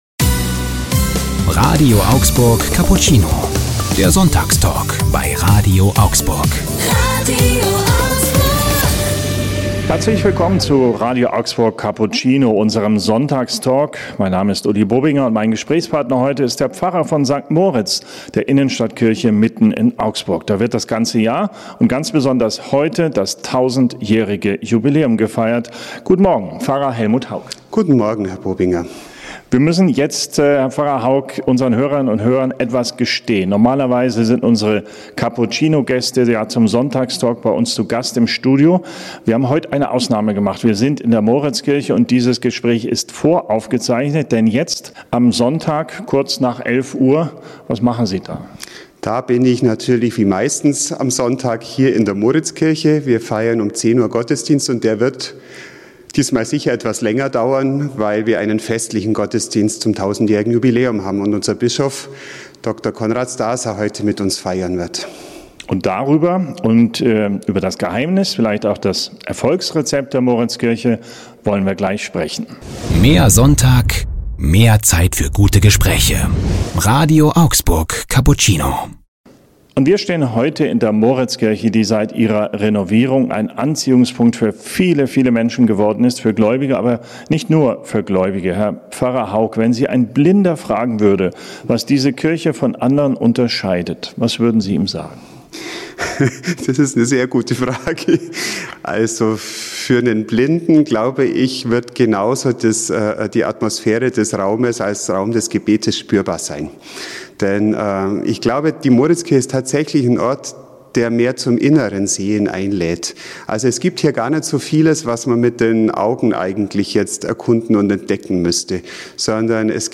1000 Jahre Moritzkirche: Talk